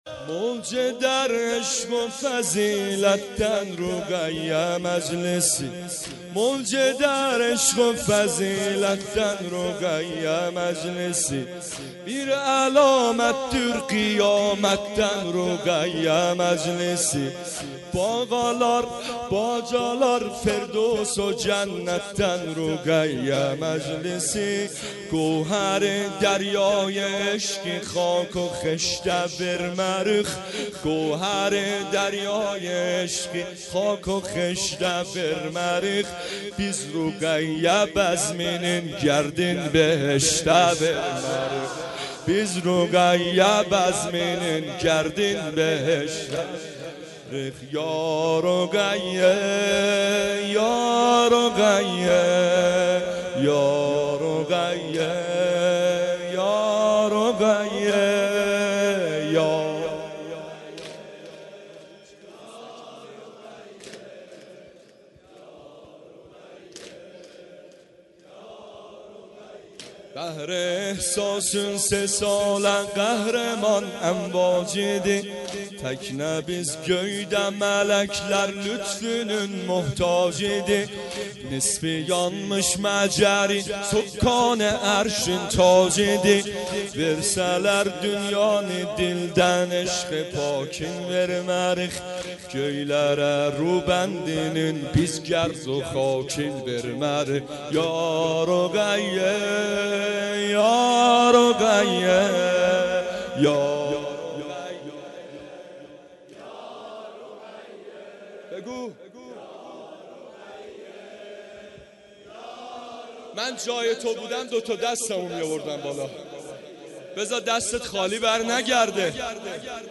سینه زنی سنگین | موج در عشق فضیلت
شب-سوم-محرم93-ashoraeian-zanjan-7.mp3